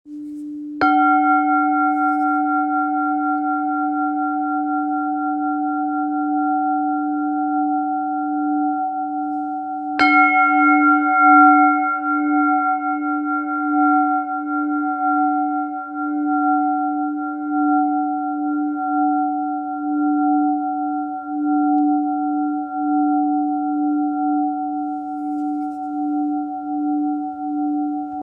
Křišťálový kalich Duch FÉNIXE - tón D, 2. sakrální čakra
Křišťálový kalich s držadlem je zdrojem stejně intenzivních vibrací jako klasické křišťálové mísy, ale díky držadlu s ním můžete volně pohybovat v prostoru i kolem těla klienta, což přináší nový typ vjemů těchto nádherných harmonizujících vibrací.
432 Hz
Kategorie: Zpívající křišťálové mísy